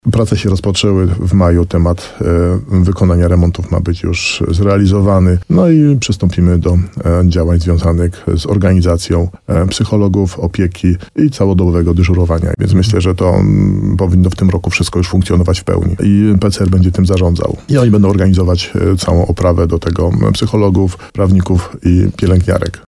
Chcemy z nim ruszyć jak najszybciej – mówi starosta nowosądecki Tadeusz Zaremba.